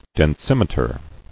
[den·sim·e·ter]